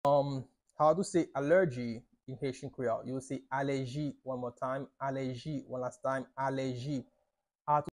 “Allergy” means "alèji" in Haitian Creole – “Alèji” pronunciation by a native Haitian Creole tutor
“Alèji” Pronunciation in Haitian Creole by a native Haitian can be heard in the audio here or in the video below:
How-to-say-Allergy-in-Haitian-Creole-–-Aleji-pronunciation-by-a-native-Haitian-Creole-teacher.mp3